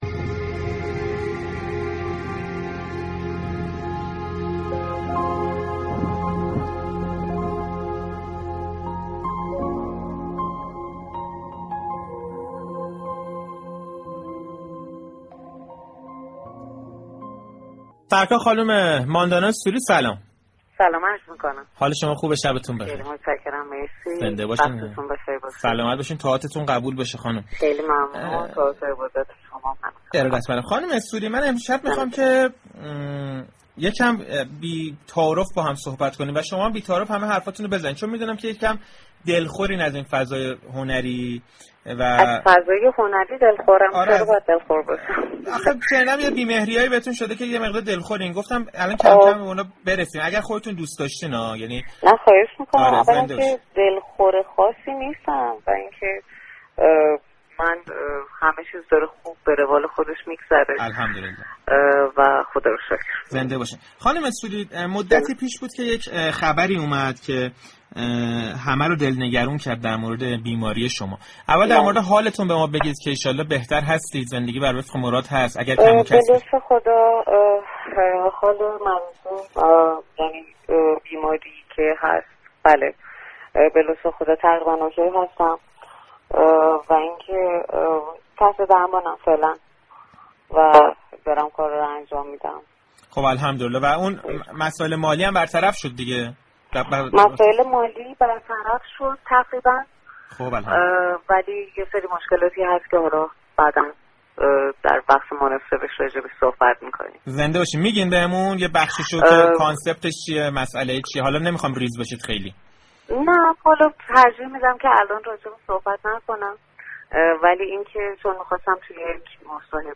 ماندانا سوری، هنرپیشه‌ی سینما و تلویزیون كه با نقش قیصرالسلطنه در سریال قهوه‌ی تلخ میان مردم شناخته شده‌تر است، 29 اردیبهشت ماه مهمان تلفنی برنامه صحنه رادیو تهران بود.